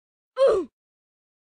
Звук смерти в Роблокс OohSFX oof